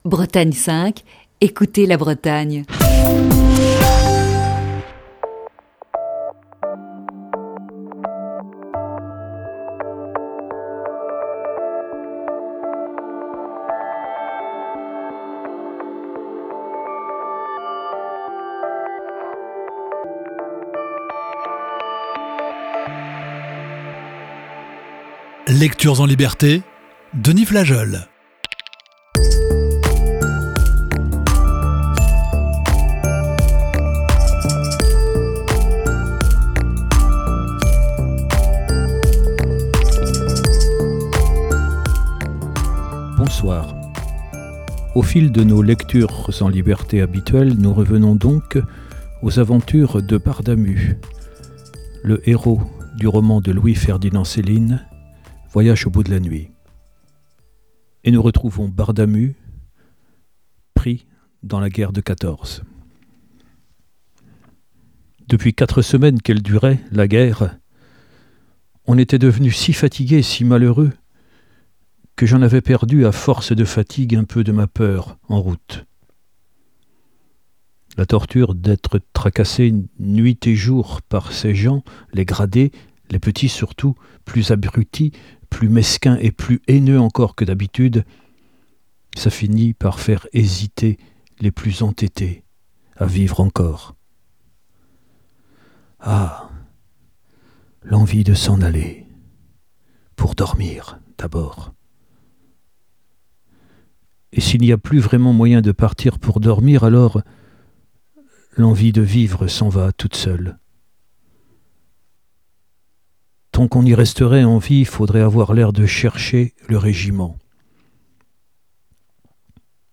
Émission du 5 février 2020.